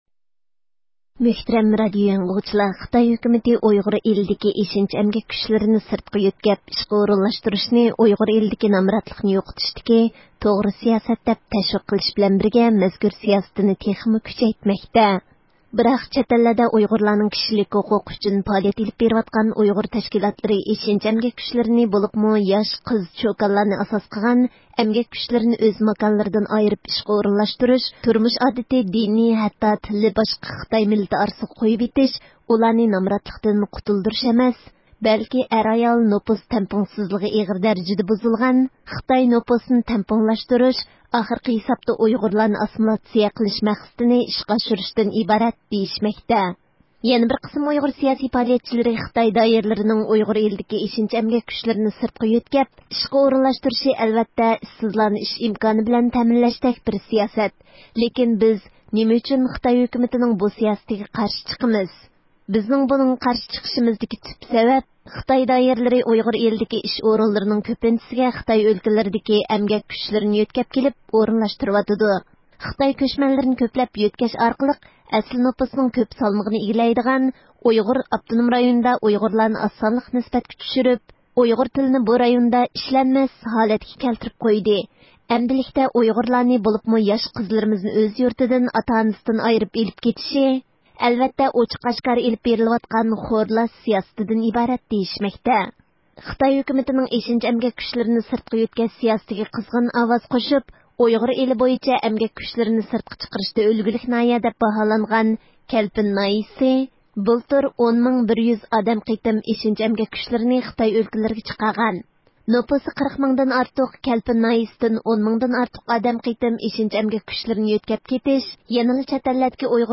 كەلپىن ناھىيىسىدىكى دېھقانلارنى تېلېفۇن ئارقىلىق زىيارەت قىلىش جەريانىدا، بىز مەزكۈر ناھىيىدە يەنە، ناماز ئوقۇغان ياشلارنىڭ، ھۆكۈمەت تەرىپىدىن قانۇنسىز دىنىي پائالىيەت بىلەن شۇغۇللانغان دېگەن باھانە بىلەن قولغا ئېلىنغانلىقىنى، ھەتتا بەزىلىرىنىڭ سوتنىڭ ھۆكۈمىسىز 6 ئايدىن ئارتۇق ۋاقىتتىن بۇيان قامىلىپ يېتىۋاتقانلىقىدىن خەۋەردار بولدۇق.